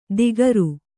♪ digaru